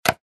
Звуки выключателя
Щелчок выключателя и свет на кухне